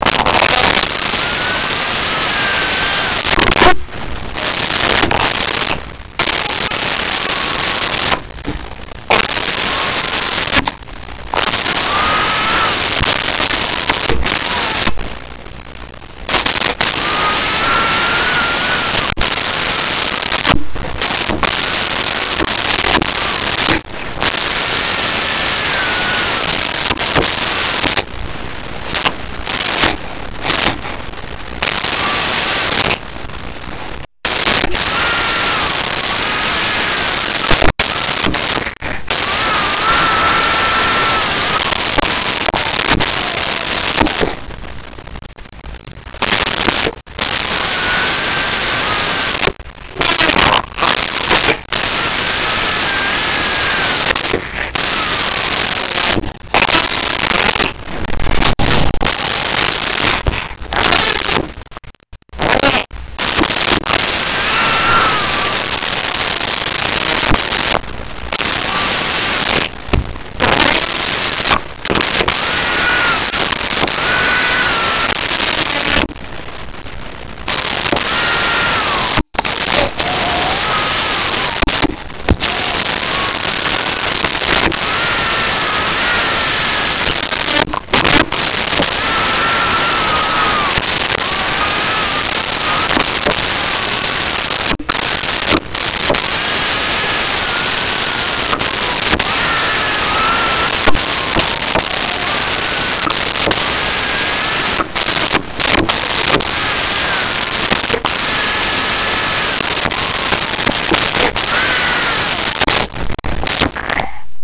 Crying Female
Crying Female.wav